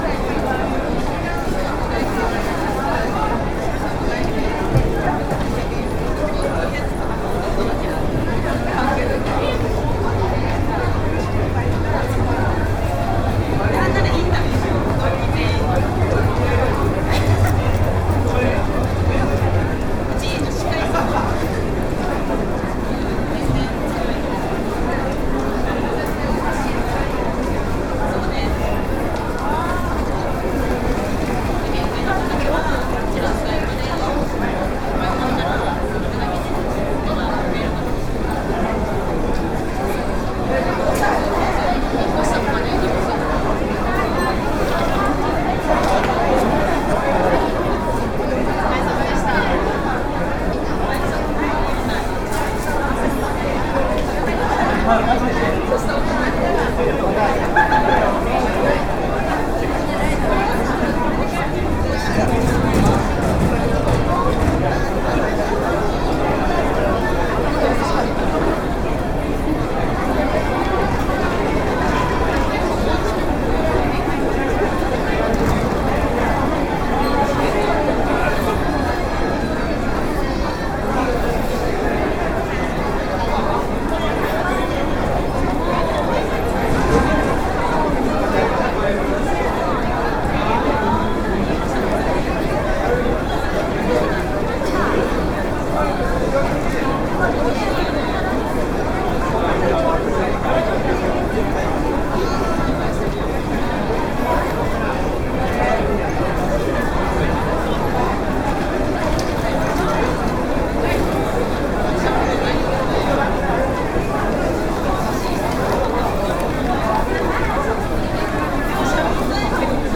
飲み帰り騒ぐ駅
bustling_station.mp3